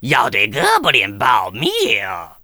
文件 文件历史 文件用途 全域文件用途 Gbn_fw_02.ogg （Ogg Vorbis声音文件，长度2.4秒，110 kbps，文件大小：32 KB） 源地址:游戏语音 文件历史 点击某个日期/时间查看对应时刻的文件。